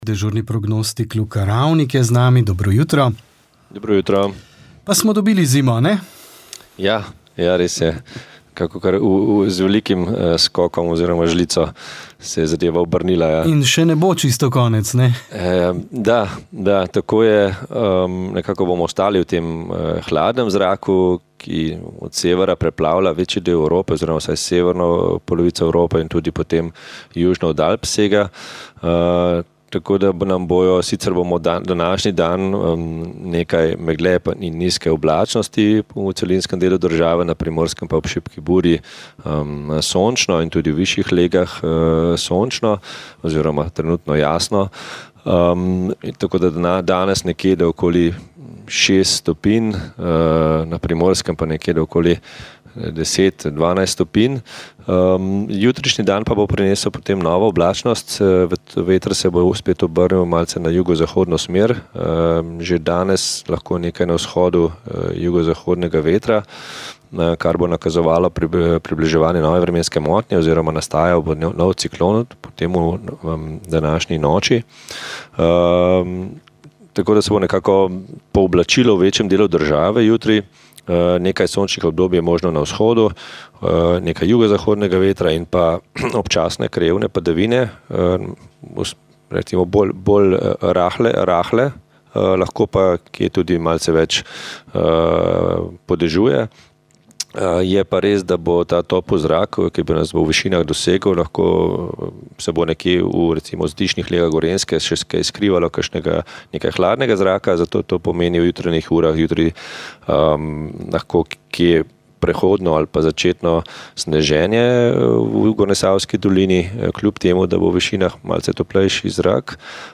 Župan Ivan Molan je v pogovoru za Radio Ognjišče izrazil presenečenje nad odločitvijo vlade glede postavitve azilnega centra v občini. Poudaril je, da občina ni bila ustrezno obveščena in da so zadevo izvedeli šele po sprejetju odločitve.